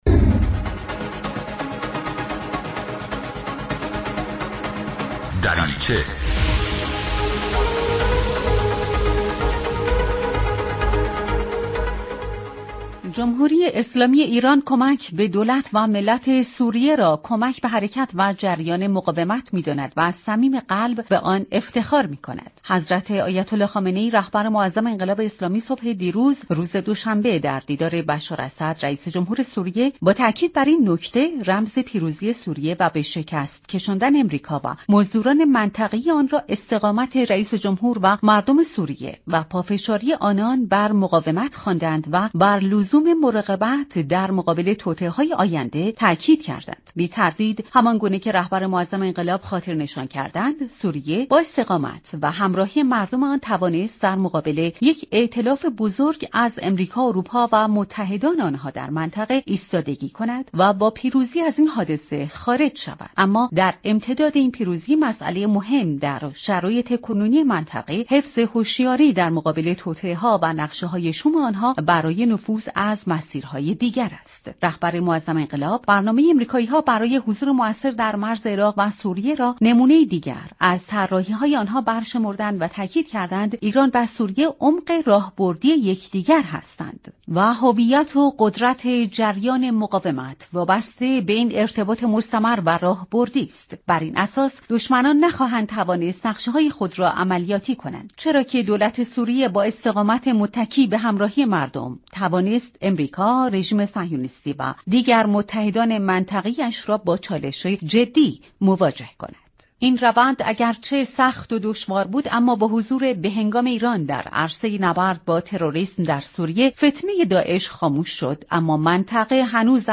كارشناس مسائل سیاسی